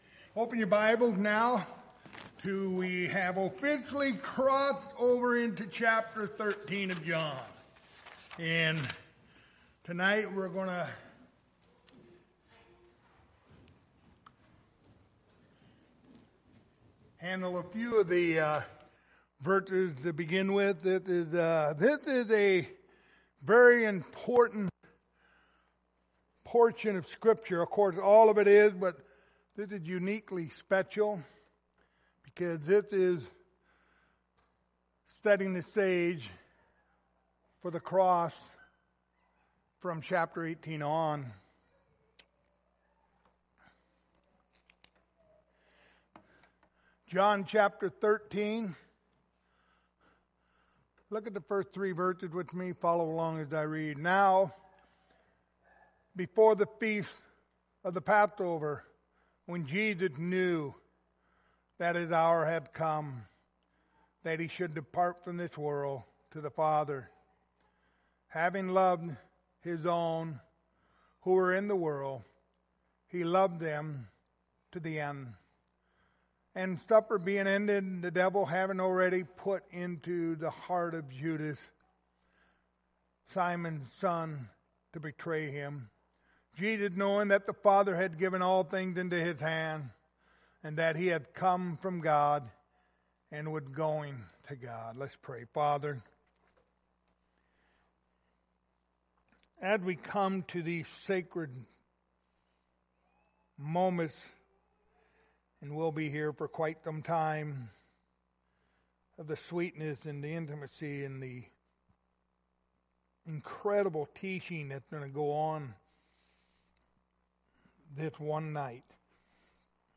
Passage: John 13:1-4 Service Type: Wednesday Evening